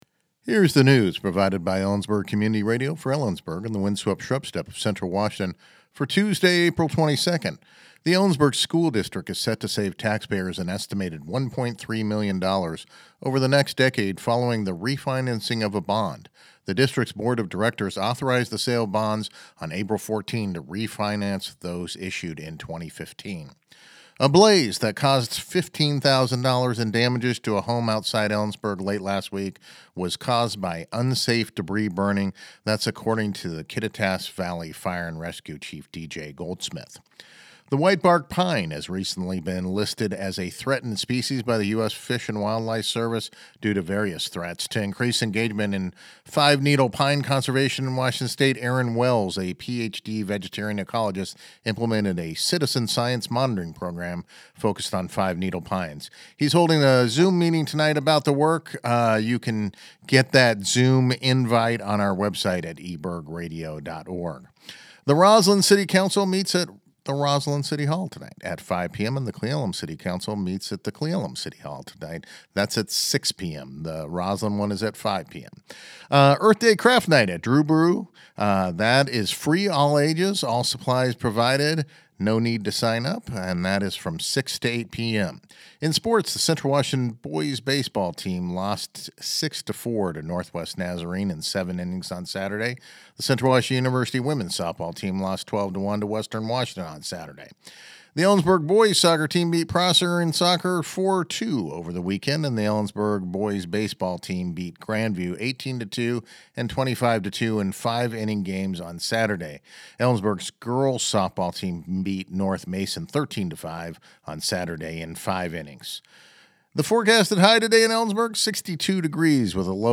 LISTEN TO THE NEWS HERENEWSThe Ellensburg School District is set to save taxpayers an estimated $1.3 million over the next decade following the refinancing of a bond.